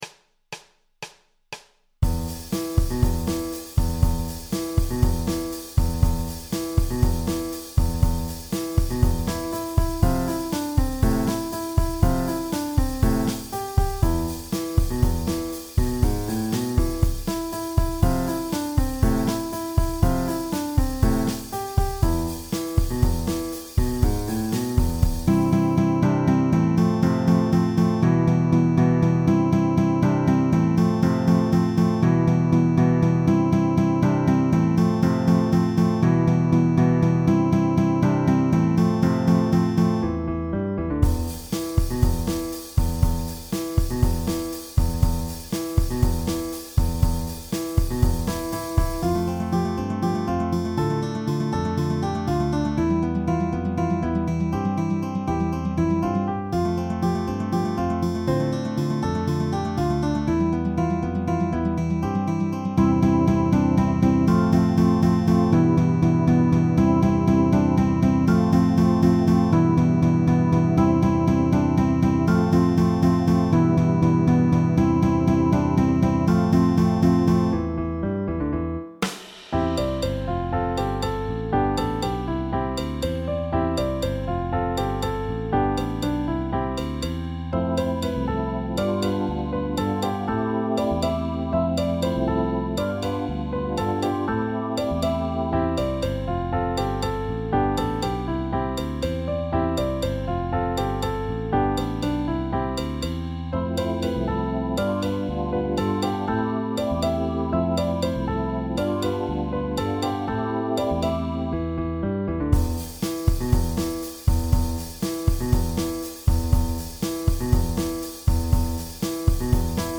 PopMusic
The title may sound rugged I know but cute tune.